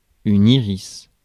Ääntäminen
Ääntäminen France: IPA: [i.ʁis] Haettu sana löytyi näillä lähdekielillä: ranska Käännös Substantiivit 1. ирис {m} 2. перуника {f} Suku: m .